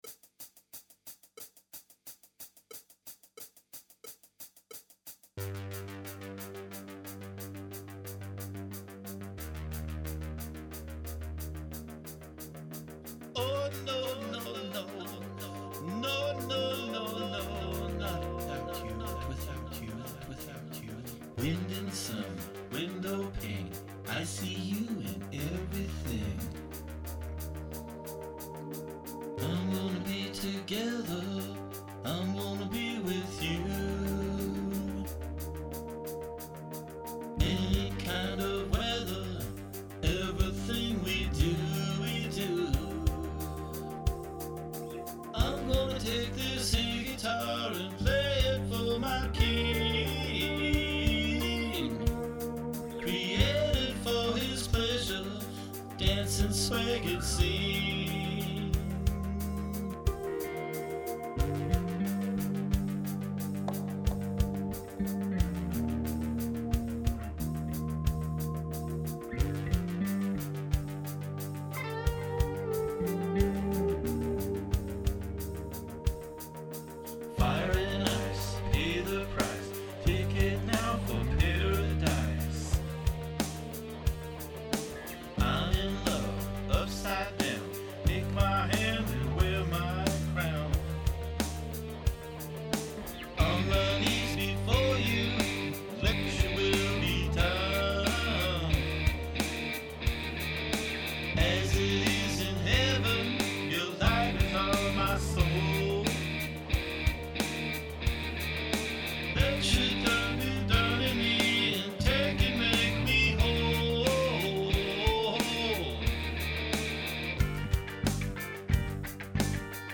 Vocals
Guitar, Bass and Keys